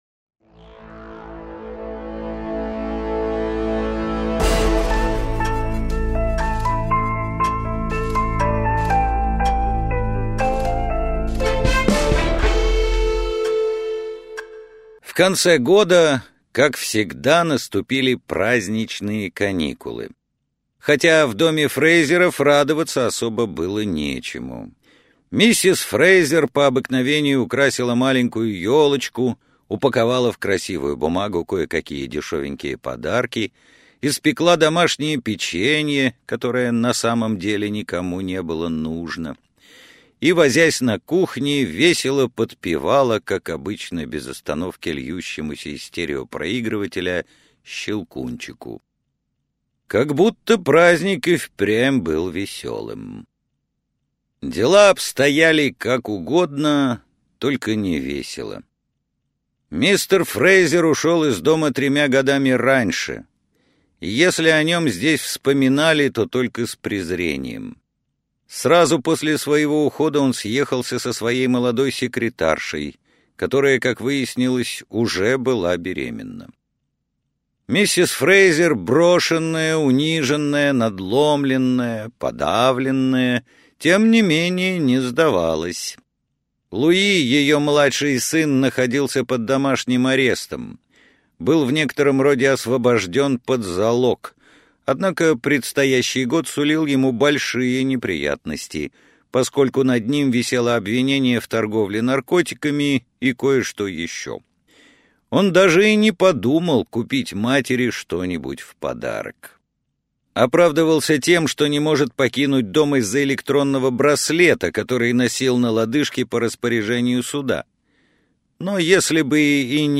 Прослушать фрагмент аудиокниги Афера